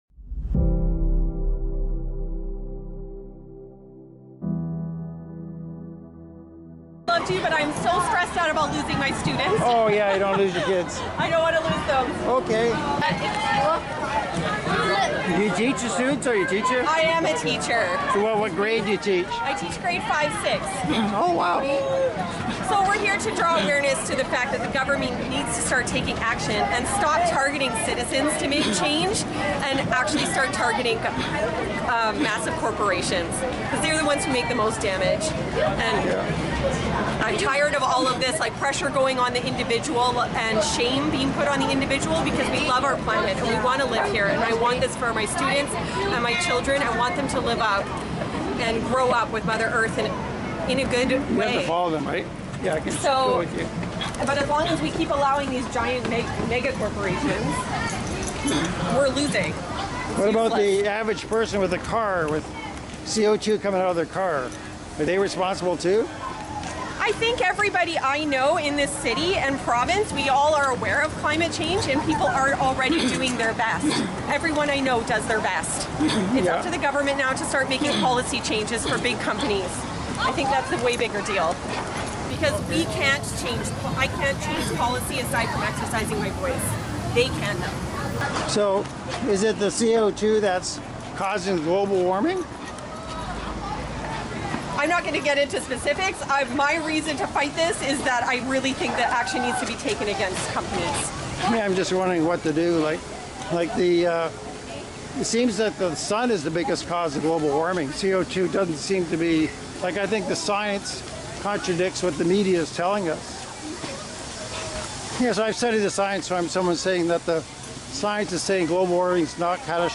2023 Deluded Global Climate Strike Sept. 15th Vancouver, Canada - Part 3 of 4